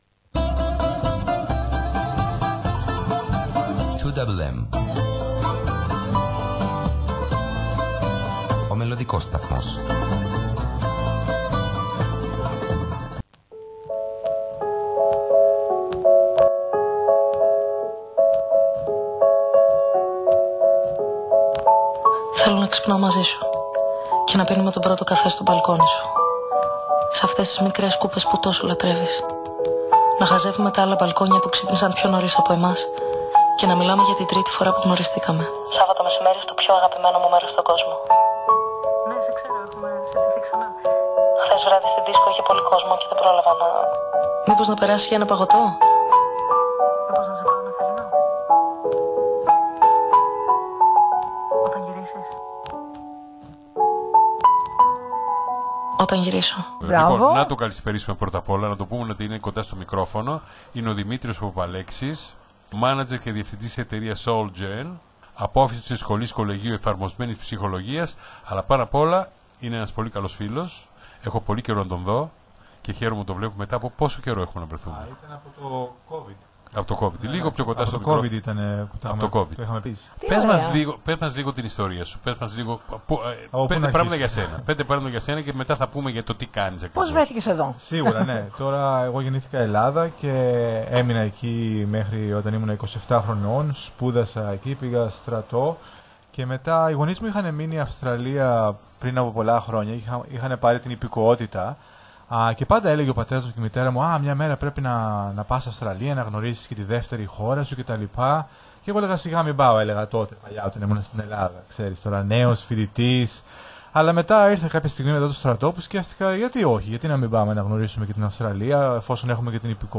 Σε μία ζωντανή συνέντευξη